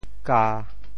How to say the words 咖 in Teochew？
TeoChew Phonetic TeoThew ga1 gia1